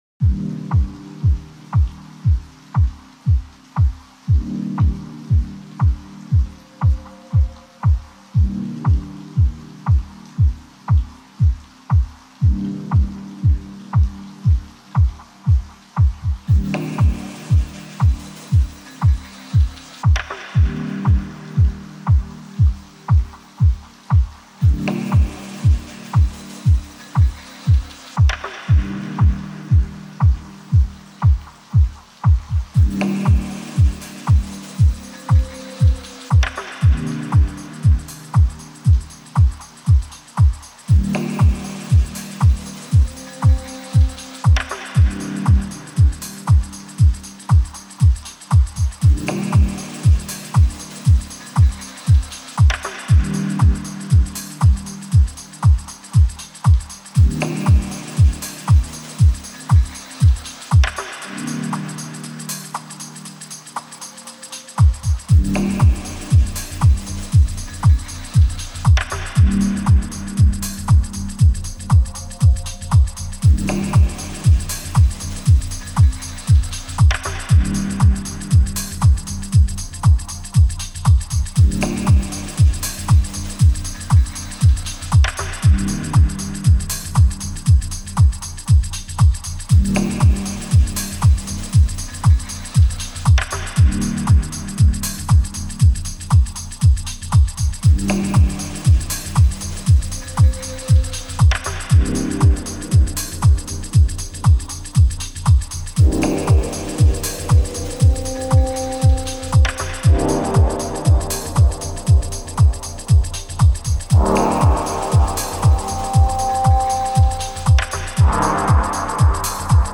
Genre: Deep House/Dub Techno.